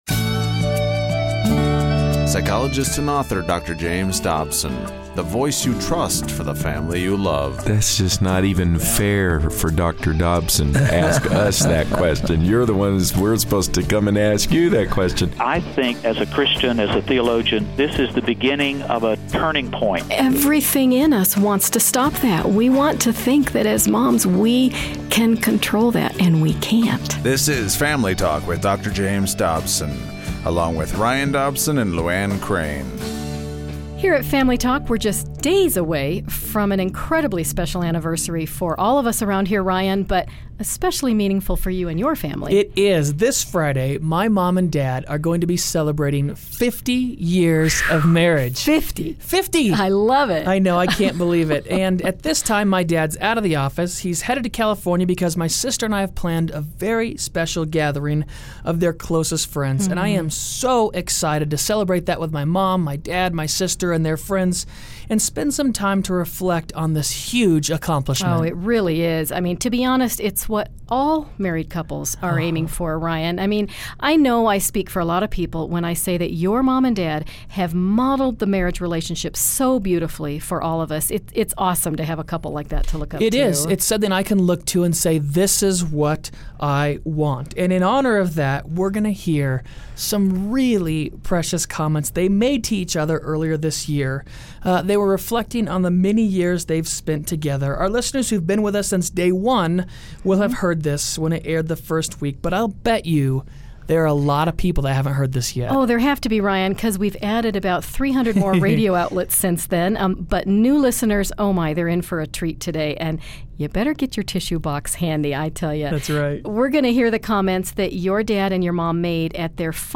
Don't miss today's program and Dr. Dobson's emotional tribute to the woman he loves!